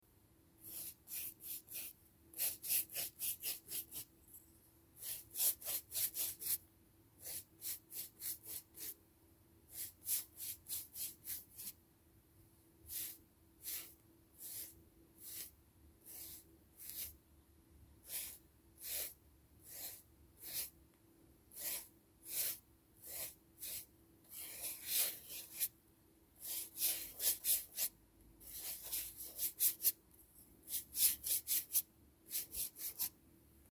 Звуки кисти
Средняя кисточка для акварельных красок по доске